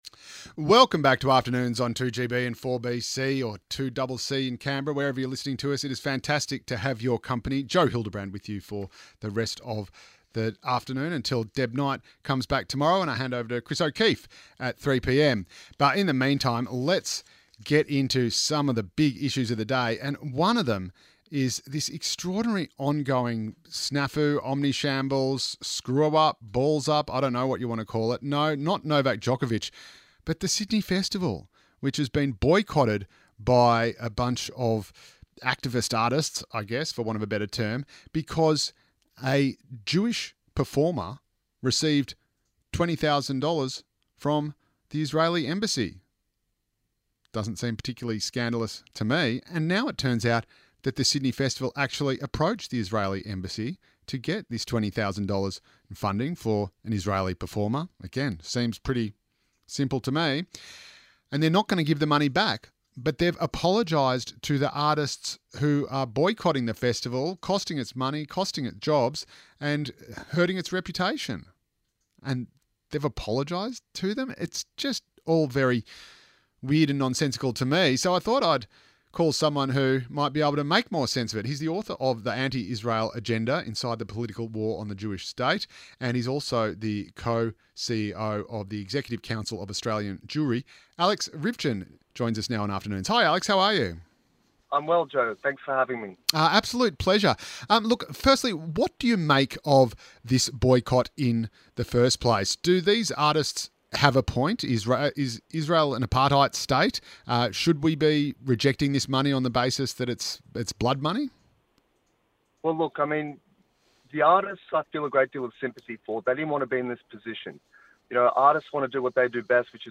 interview on 2GB Radio